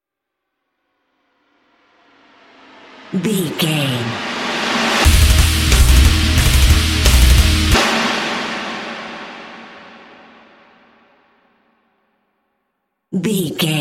Aeolian/Minor
E♭
drums
electric guitar
bass guitar
hard rock
lead guitar
aggressive
energetic
intense
nu metal
alternative metal